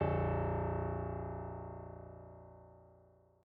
piano-sounds-dev
SoftPiano